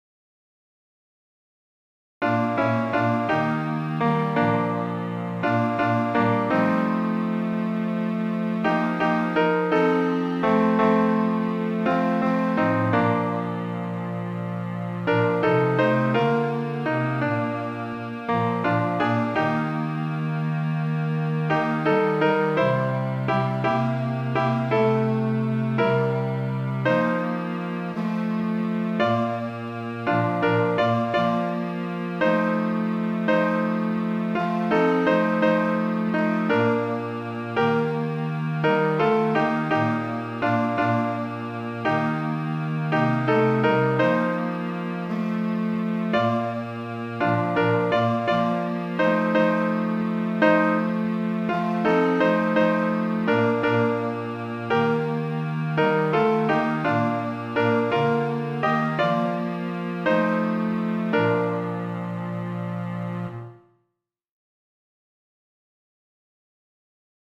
Piano & Clarinet Play the mp3 file